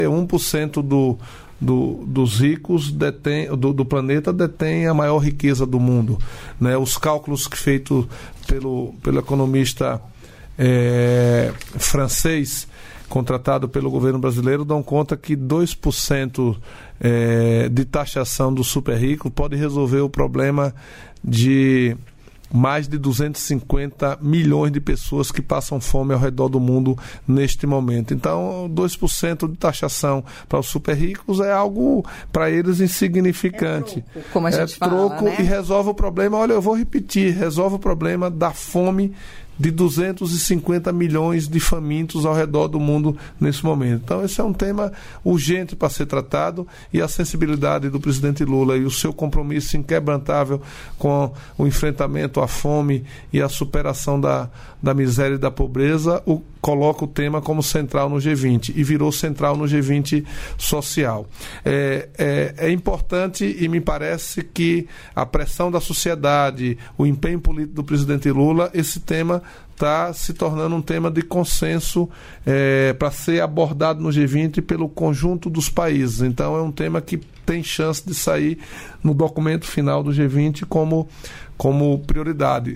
Trecho da participação do ministro da Secretaria-Geral da Presidência da República, Márcio Macêdo, no programa Bom Dia, Ministro desta quinta-feira (7), nos estúdios da EBC, em Brasília.